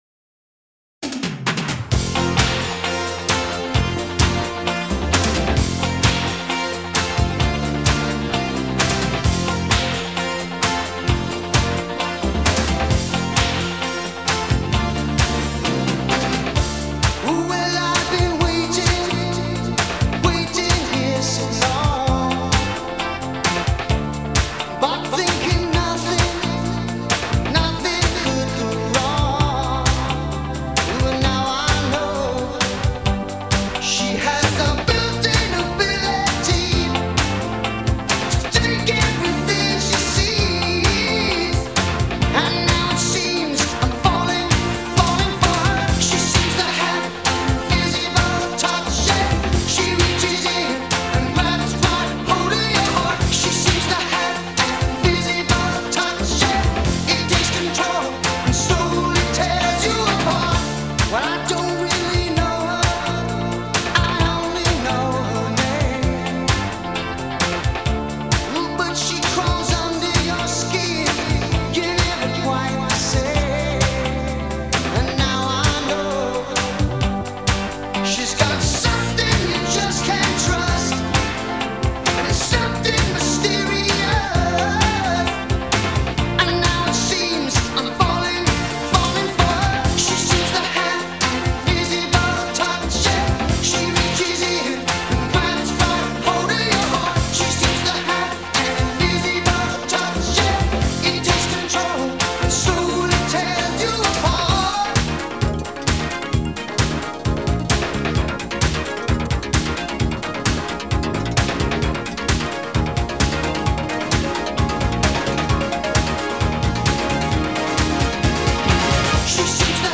Synth-Pop (80'erne)